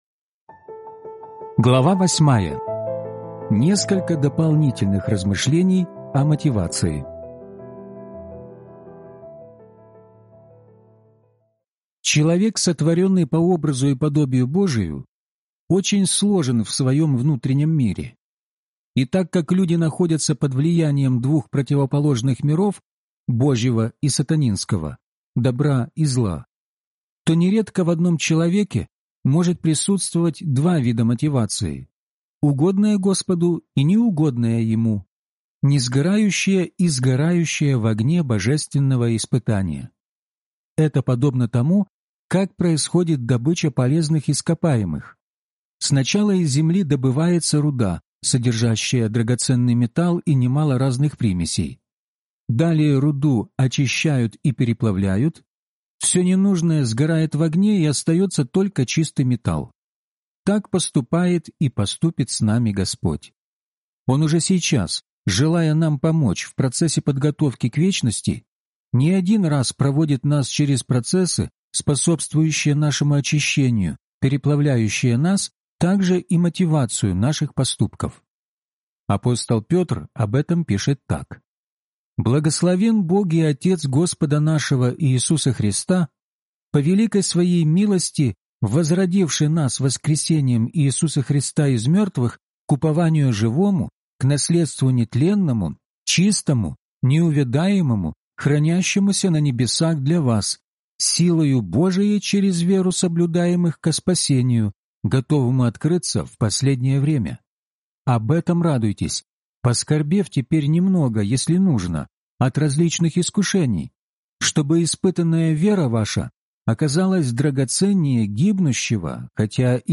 Судилище Христово (аудиокнига) - День 8 из 12